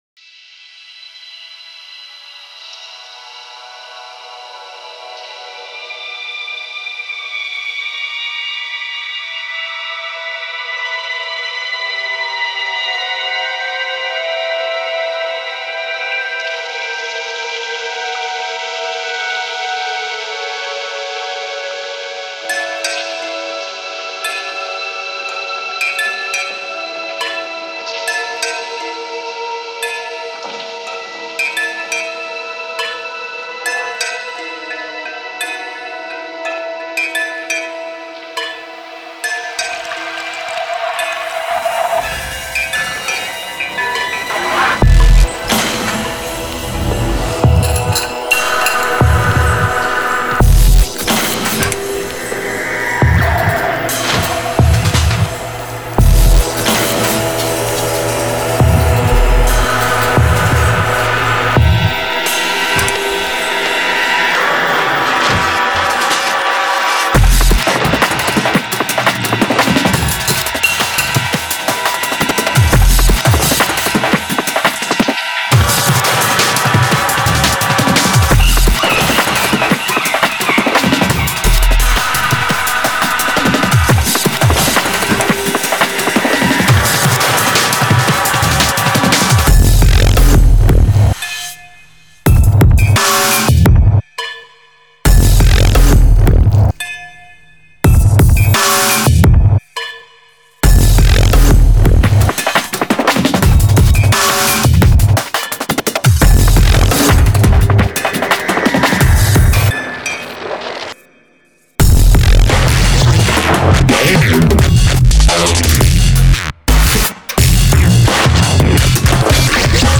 Style: Dubstep, Drum & Bass